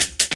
41 HH 01  -L.wav